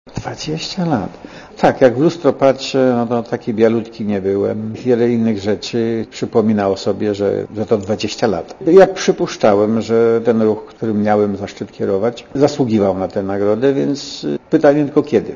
Kto by pomyślał, że było to tak dawno - mówi Radiu Zet prezydent Lech Walesa.
Komentarz audio